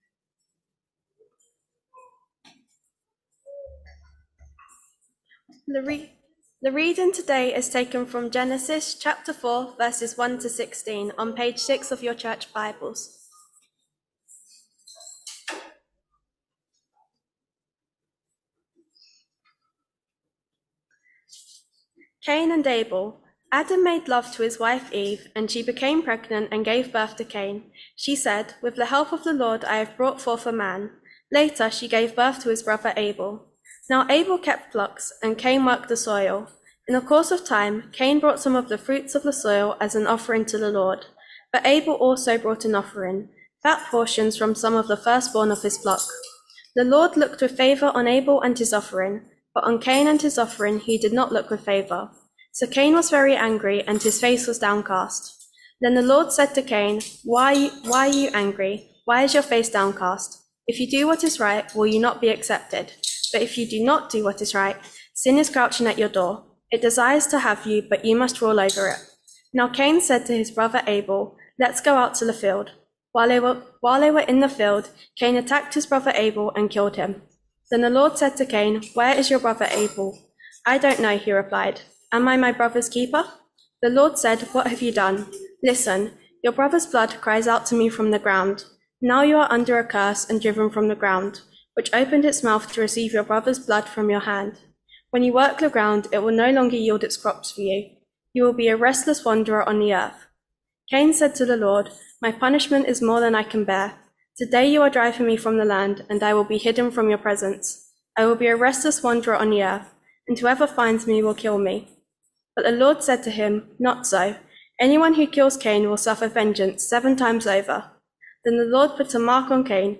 Genesis 4vs1-16 Service Type: Sunday Morning All Age Service Topics